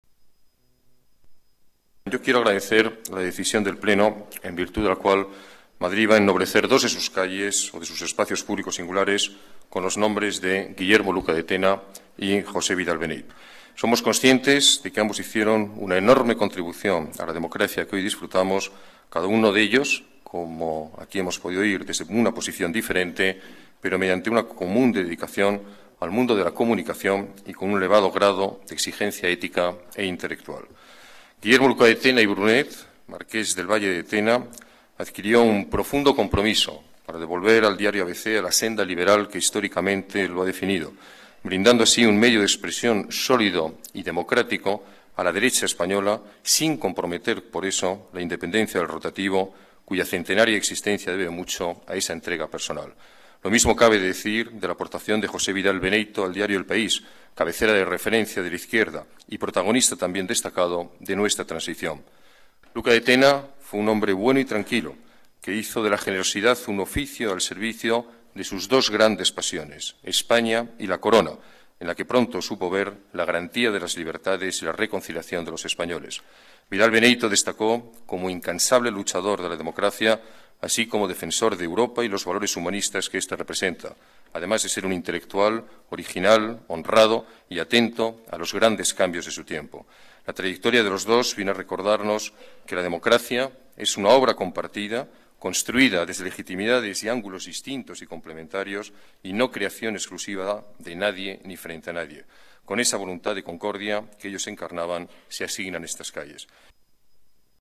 Nueva ventana:Declaraciones del alcalde sobre el reconocimiento a Luca de Tena y Vidal Beneyto